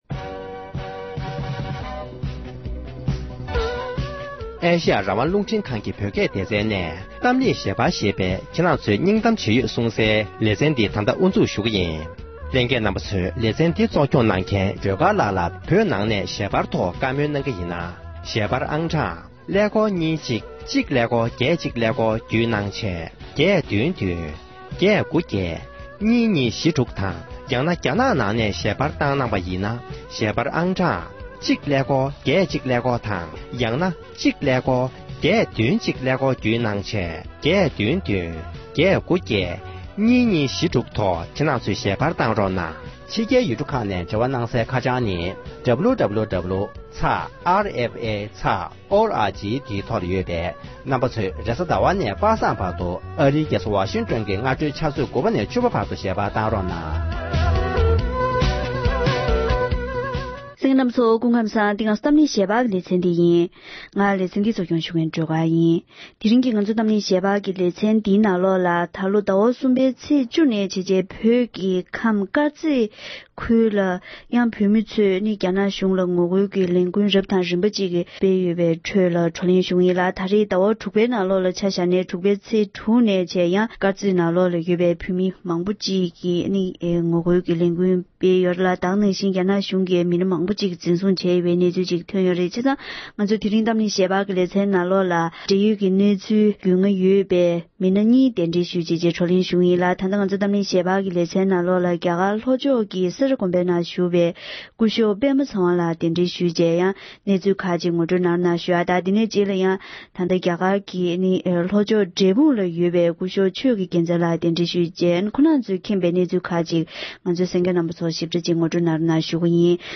འབྲེལ་ཡོད་མི་སྣ་དང་བགྲོ་གླེང་ཞུས་པ་ཞིག་ལ་གསན་རོགས་ཞུ༎